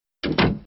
TrainDoor.wav